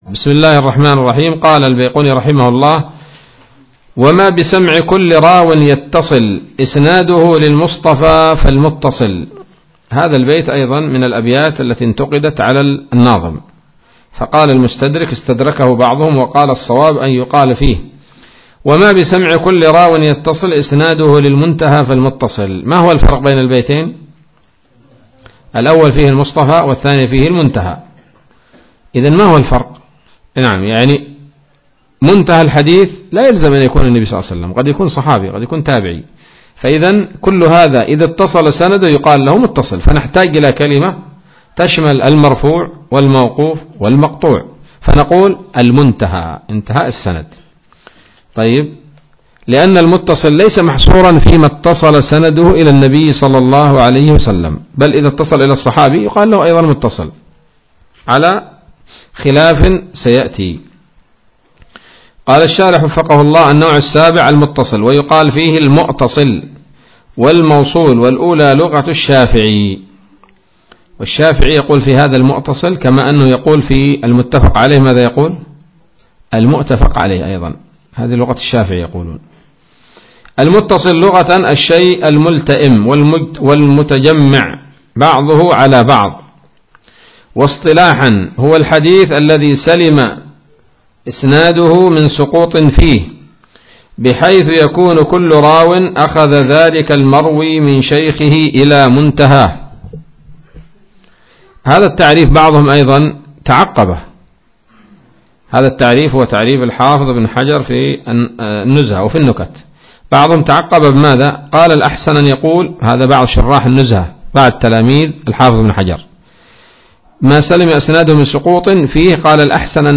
الدرس الثالث عشر من الفتوحات القيومية في شرح البيقونية [1444هـ]